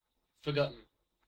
Uttal
Uttal GenAm: IPA : /fɚ.ˈɡɑt.n̩/ US: IPA : [fɚ.ˈɡɑt.n̩] New England: IPA : /fəˈɡʌt.n̩/ IPA : /fɚˈɡʌt.n̩/ RP: IPA : /fə.ˈɡɒt.n̩/ Ordet hittades på dessa språk: engelska Ingen översättning hittades i den valda målspråket.